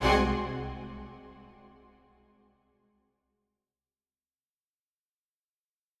an orchestra performing
Strings Hit 2 Staccato
Bring new life to your videos with professional orchestral sounds.
A staccato is a short and fast sound that any orchestral instrument can make.  In this sample, you hear four sections of four different instruments from the orchestra which are violins, violas, violoncellos and double basses.
Strings-Hit-2-Staccato.wav